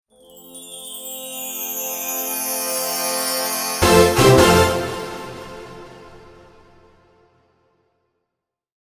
Winning Trumpet Sound Effect
Triumphant trumpet sound. Dynamic, short, and sharp.
Genres: Sound Logo
Winning-trumpet-sound-effect.mp3